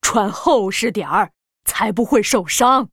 文件 文件历史 文件用途 全域文件用途 Balena_fw_03.ogg （Ogg Vorbis声音文件，长度2.9秒，92 kbps，文件大小：33 KB） 源地址:游戏语音 文件历史 点击某个日期/时间查看对应时刻的文件。